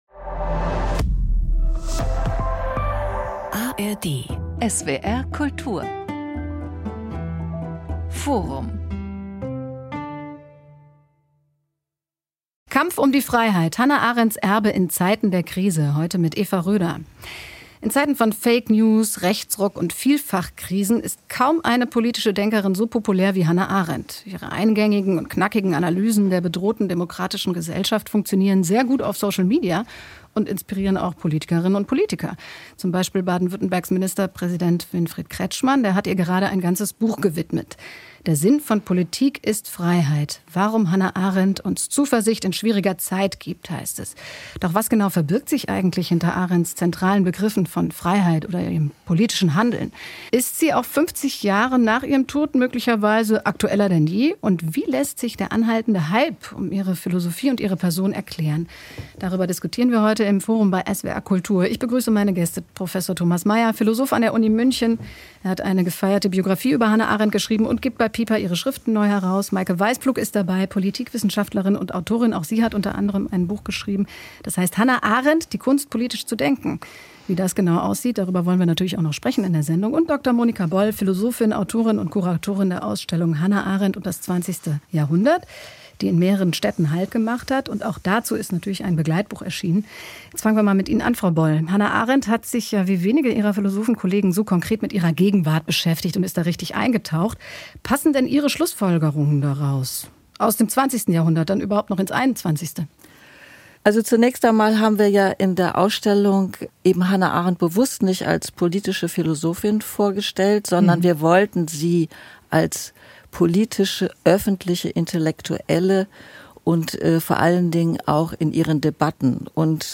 Philosophin, Publizistin, Kuratorin
Autorin und Journalistin Mehr